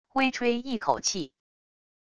微吹一口气wav音频